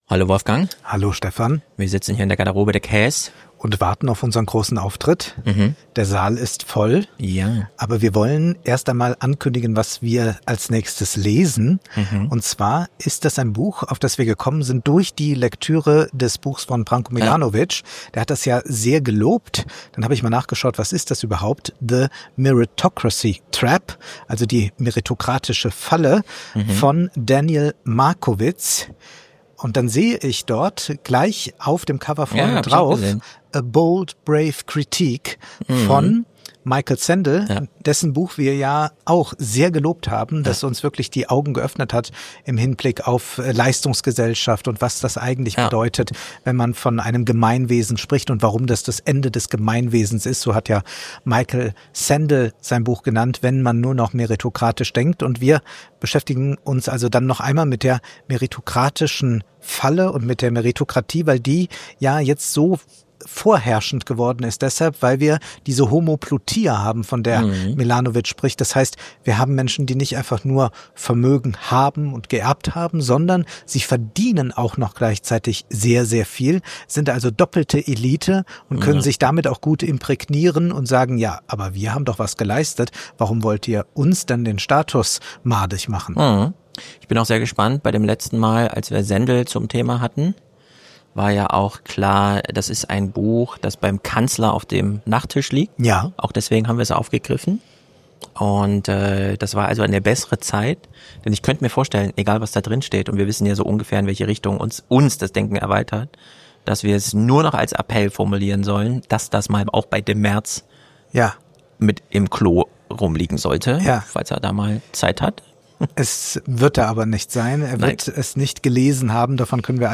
Live in Frankfurt: Virtuelle Kommunalpolitik & Reale Matrixmedien